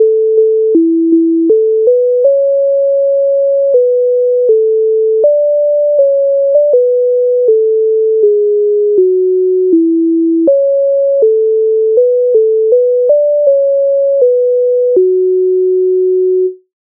MIDI файл завантажено в тональності A-dur
Ой піду я в сад гулять Українська народна пісня з обробок Леонтовича с. 154
Ukrainska_narodna_pisnia_Oj_pidu_ia_v_sad_huliat.mp3